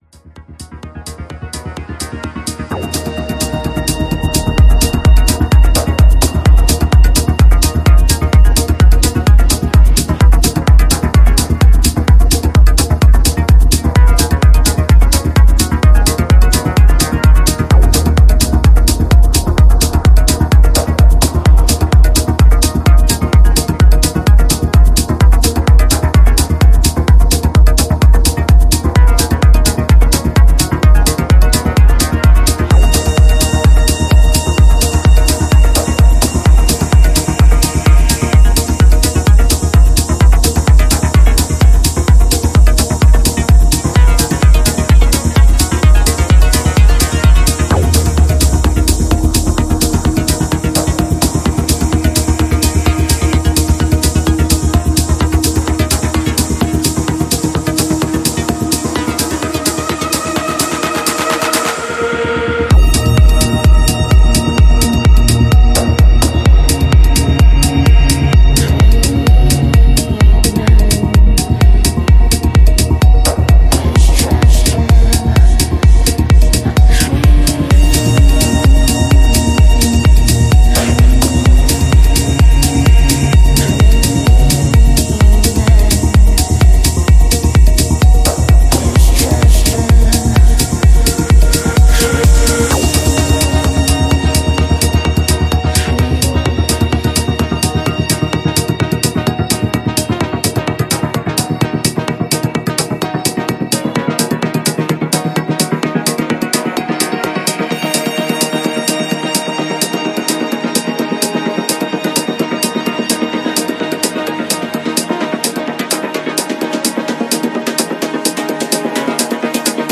Genre: Melodic Techno